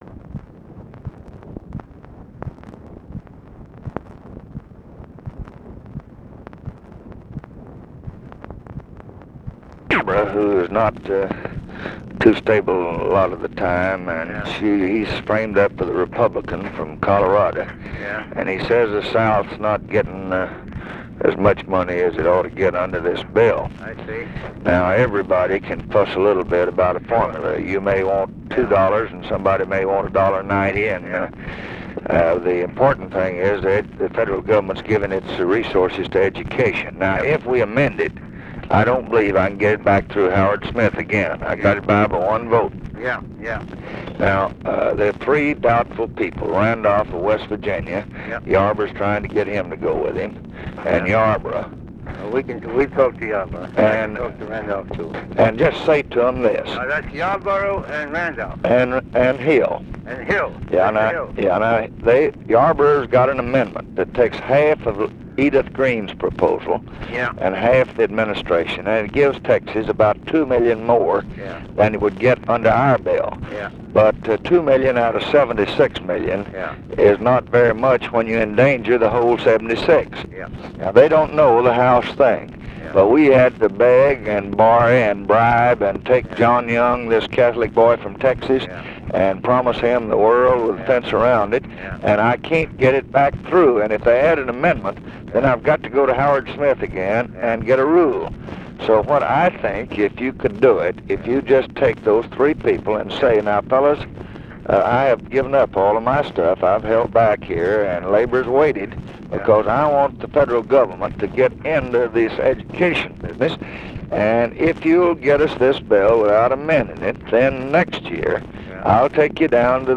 Conversation with GEORGE MEANY, April 1, 1965
Secret White House Tapes